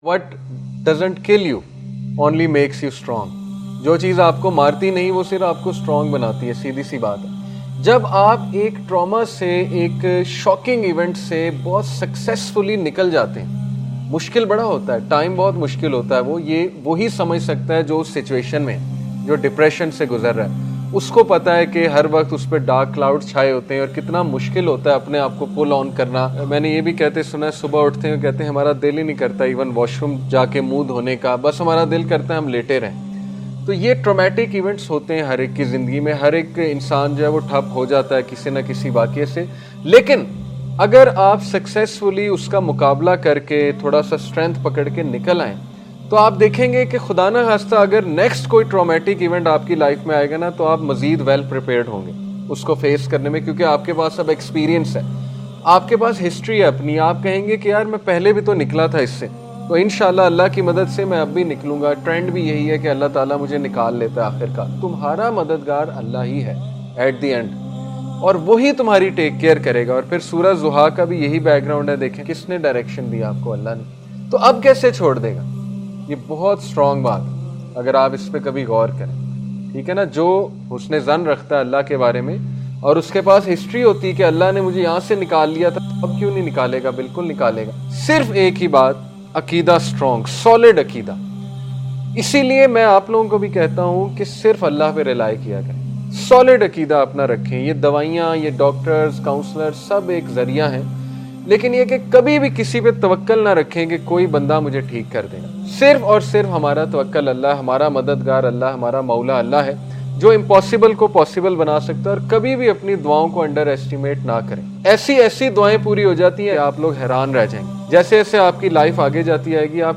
Accept Your Pain ｜｜ Emotional Reminder